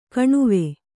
♪ kaṇuve